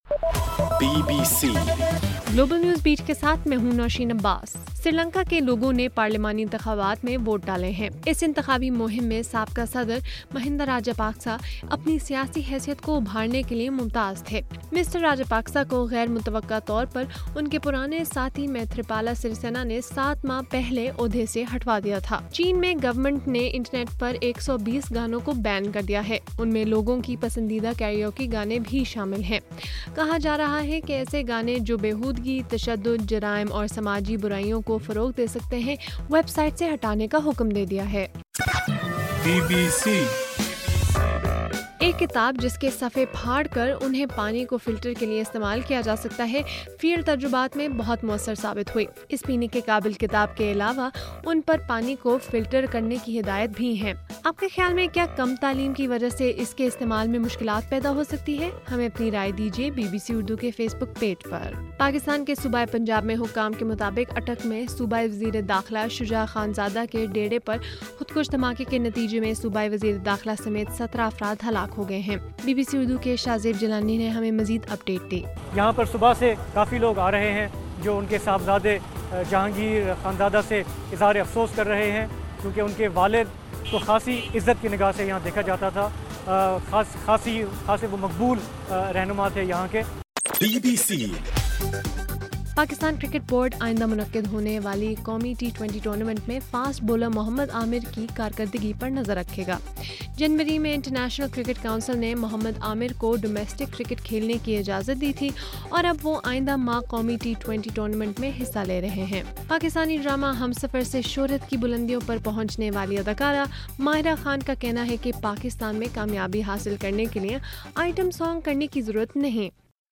اگست 18:صبح1 بجے کا گلوبل نیوز بیٹ بُلیٹن